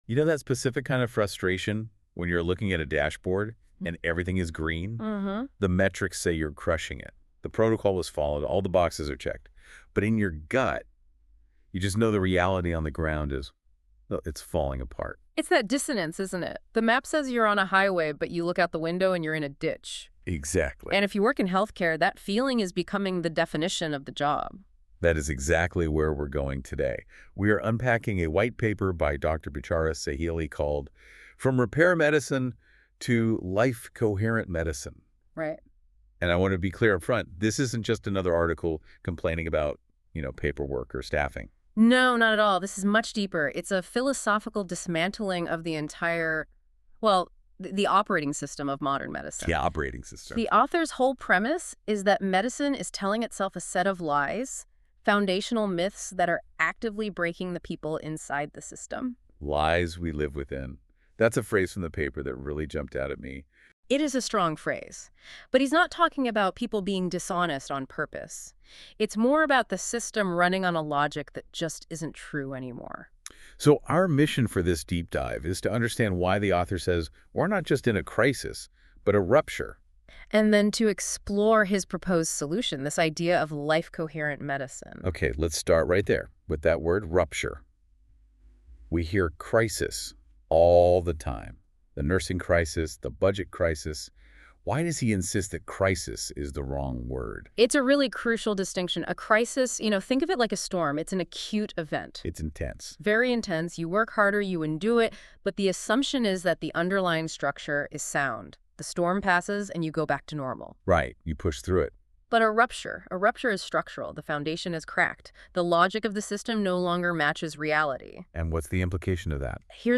From Repair Medicine to Life-Coherent Medicine: Exposing the Clinical Lies We Live Within and Designing for Viability | ChatGPT5.2 & NotebookLM - TOWARDS LIFE-KNOWLEDGE